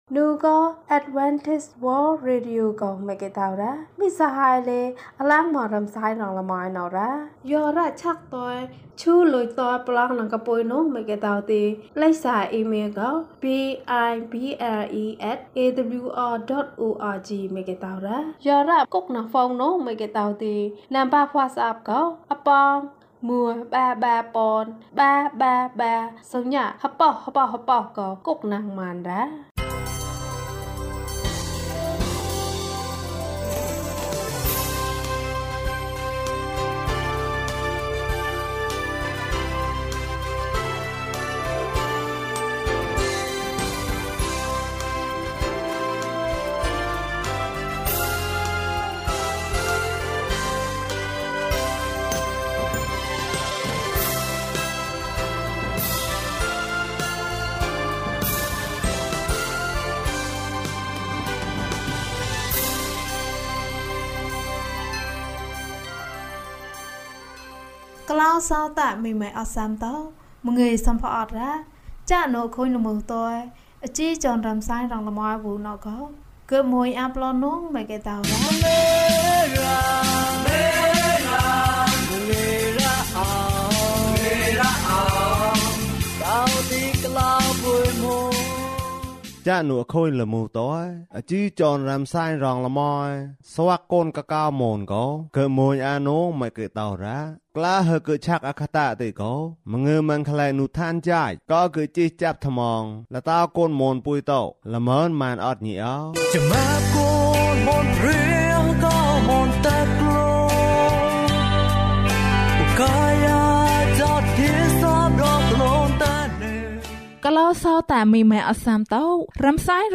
ဘုရားသခင်သည် ချစ်ခြင်းမေတ္တာဖြစ်သည်။၀၆ ကျန်းမာခြင်းအကြောင်းအရာ။ ဓမ္မသီချင်း။ တရားဒေသနာ။